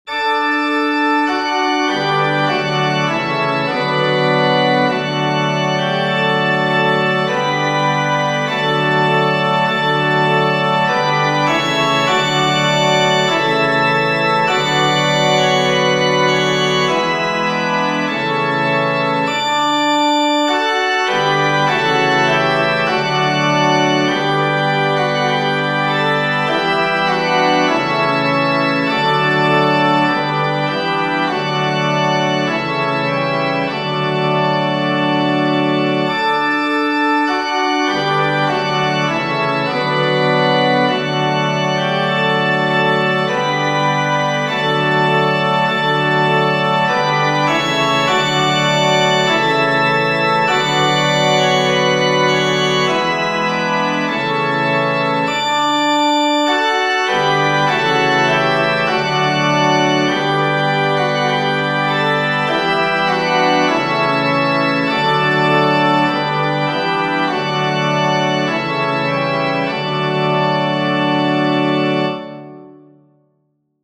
Tradizionale Genere: Religiose Testo italiano di G. Poma, melodia tradizionale.